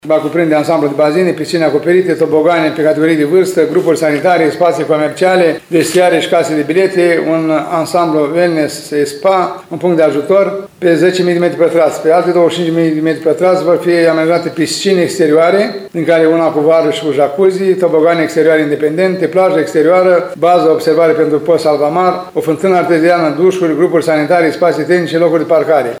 Primarul ION LUNGU a detaliat investiția care urmează să ocupe o suprafață de 3 hectare și jumătate, situată la ieșirea din Suceava spre Fălticeni.